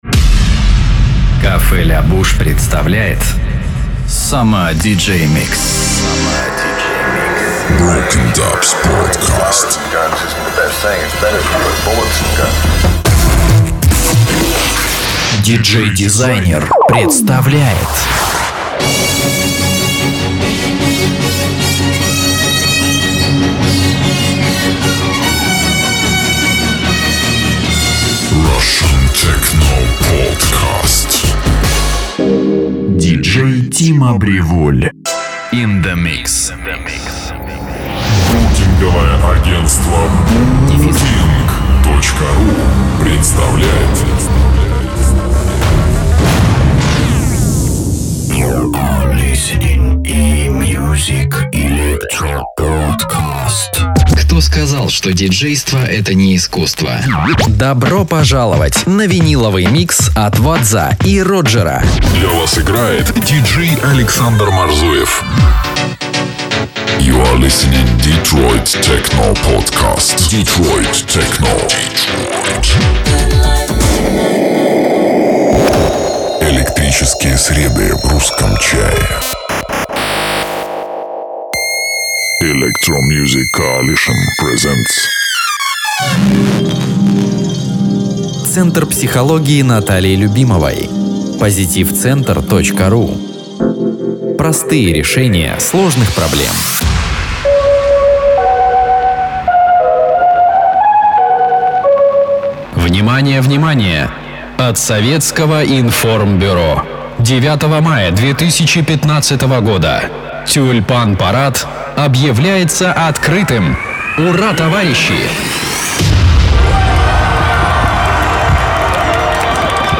Муж, Другая
Rode NT1 Rode NTG2 ISK BM-800 Октава МК-012 ART Voice Channel Presonus StudioLive 16.0.2 ESI Juli@